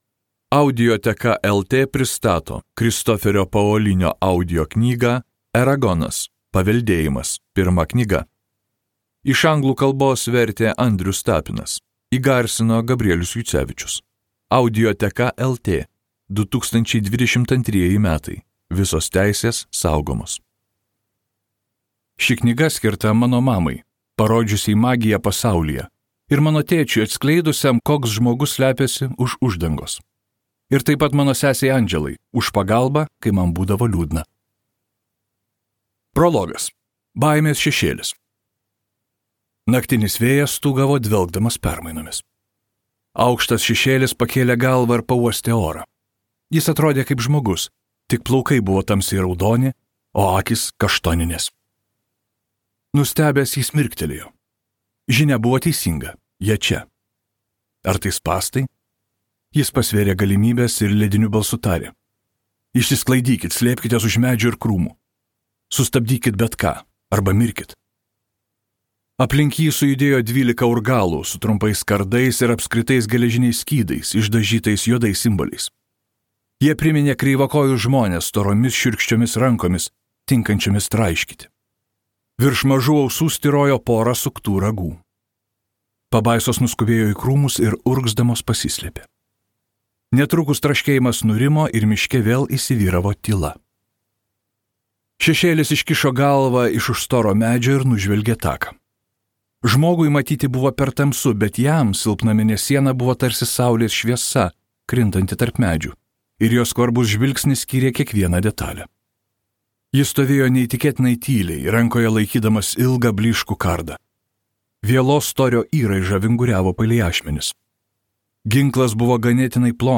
Tai magiškas pasakojimas apie drakonus.